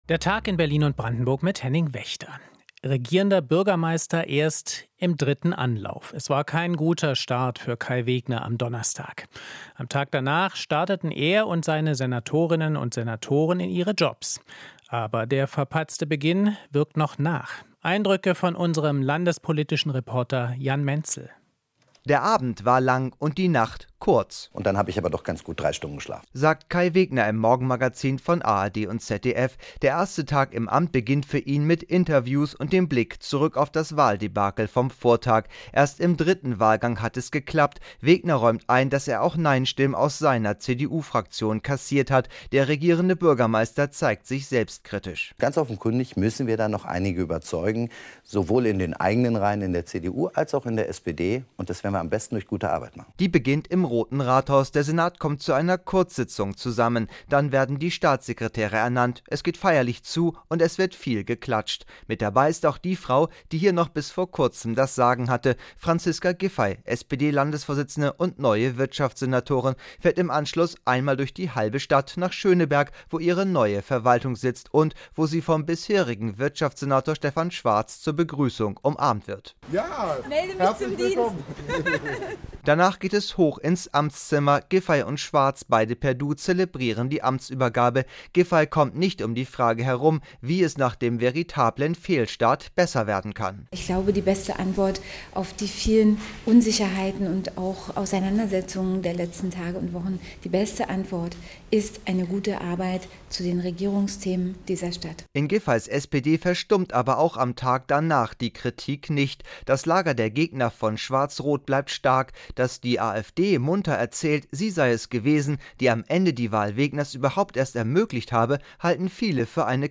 Inforadio Nachrichten, 28.04.2023, 19:30 Uhr - 28.04.2023